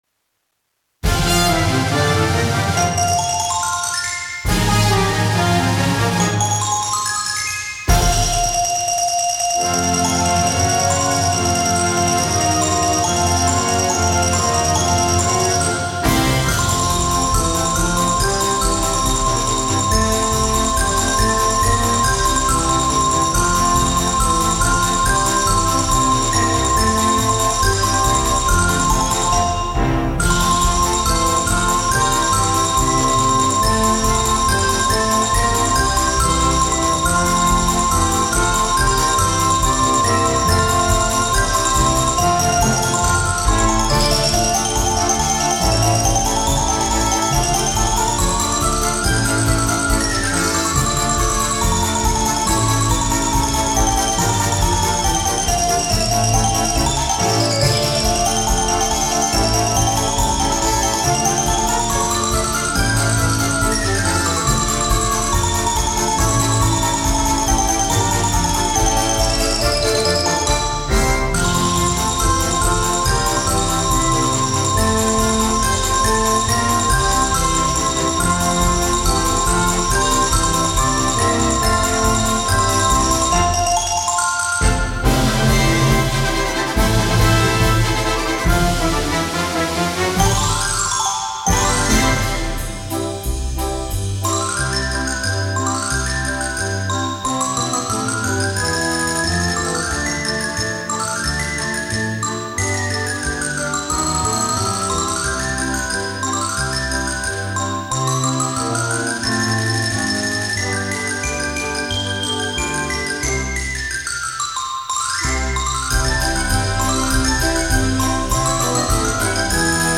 for Xylophone and Wind Band
CategoryXylophone Solo
InstrumentationSolo Xylophone